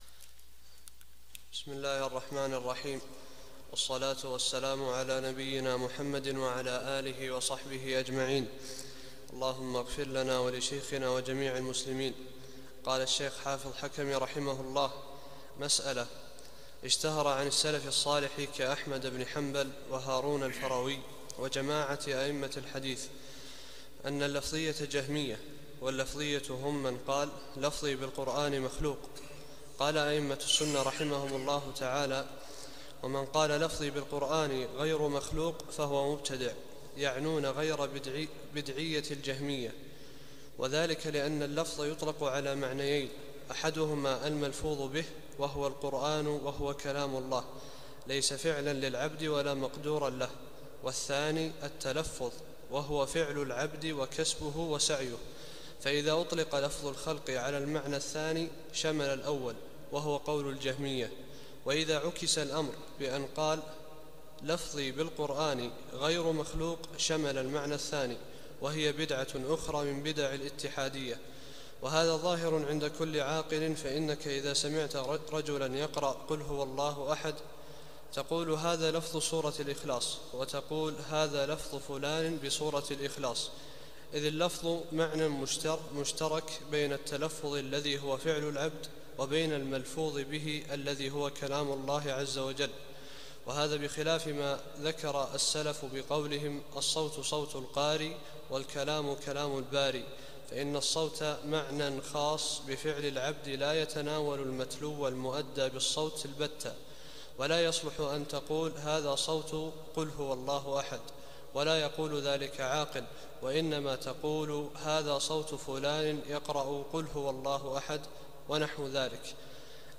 43- الدرس الثالث والأربعون